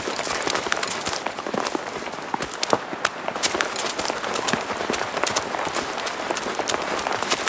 Sfx_creature_snowstalkerbaby_run_loop_01.ogg